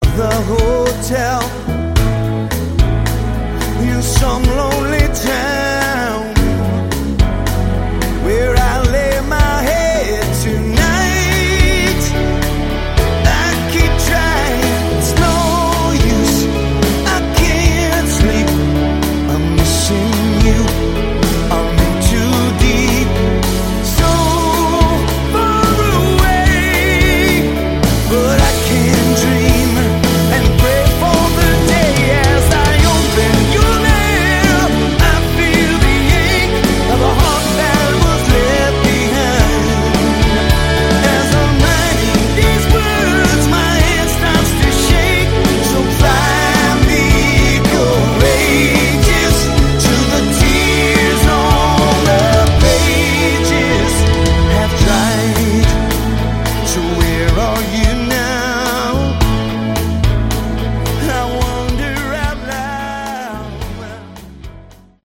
Category: AOR
vocals
bass, background vocals
guitars
drums
keyboards